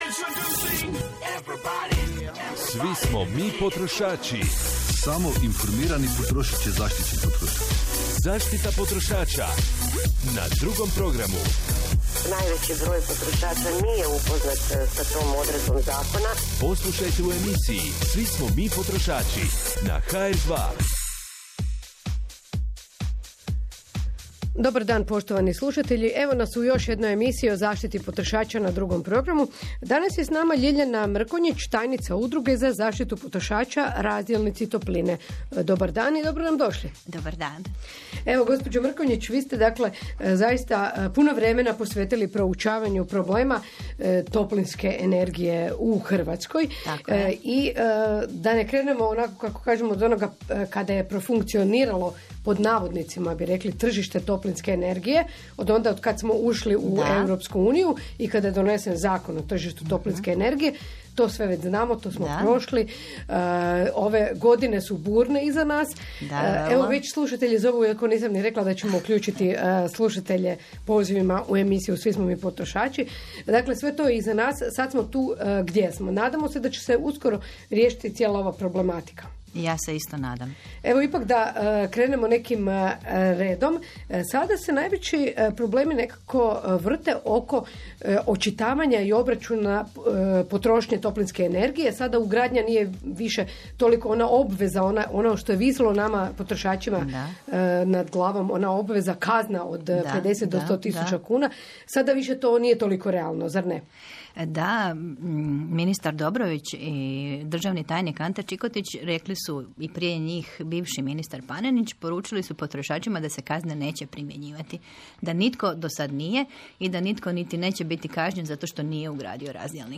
Odgovore na ta i još mnoga druga pitanja o razdjelnicima topline poslušajte u audio-zapisu radio-emisije Hrvatskog radija 2 “Svi smo mi potrošači”.